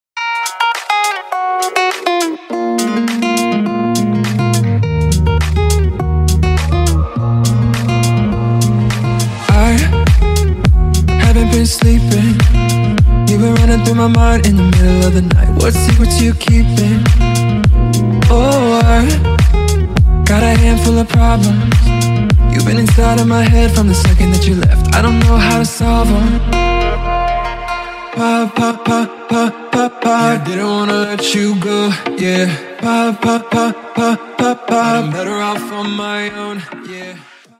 • Качество: 128, Stereo
гитара
ритмичные
мужской вокал
dance
Dance Pop